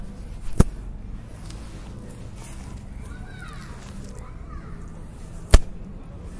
描述：塑料色带。
来自Escola Basica Gualtar（葡萄牙）及其周围环境的现场录音，由8岁的学生制作。